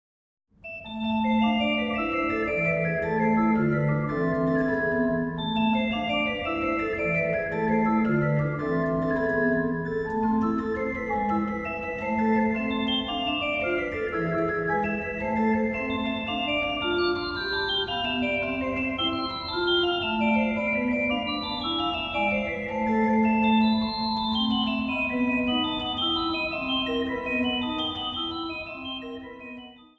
Instrumentaal | Blokfluit
Instrumentaal | Carillon
Instrumentaal | Klavecimbel